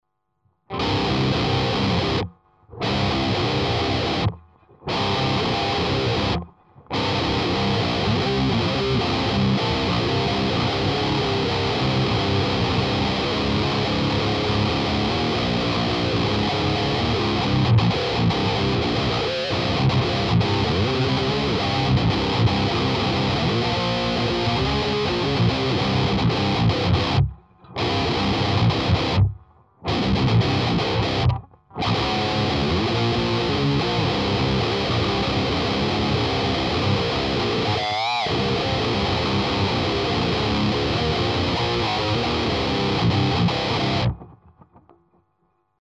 примеров звука именно этого типа перегруза ("Metall") не меняя ручек настроек, а меняя лишь типы кабинетов.
Metall+UK H30
(Гитара Ibanez RG-270, строй Drop C , струны 13-68).